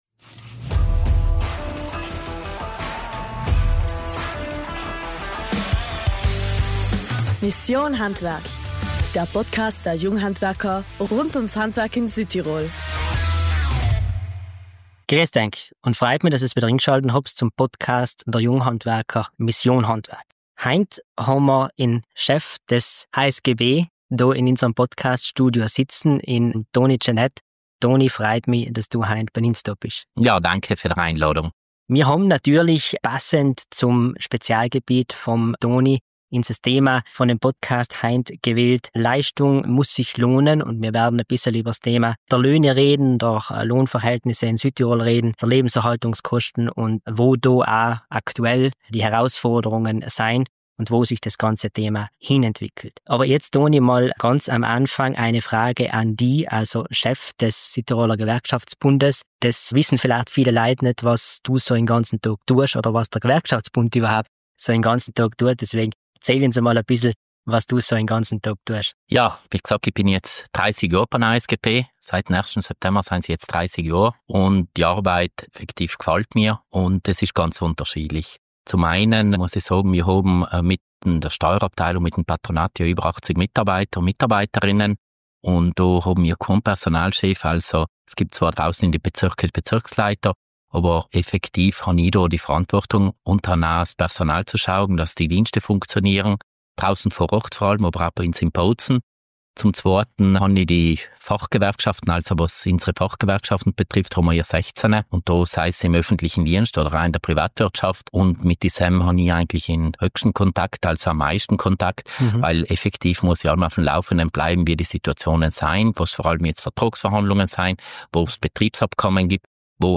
Zudem spricht er über das Thema Löhne und wie die Gehaltsstrukturen in Südtirol funktionieren. Ein aufschlussreiches Gespräch über die wirtschaftlichen und sozialen Themen, die Südtirol derzeit prägen.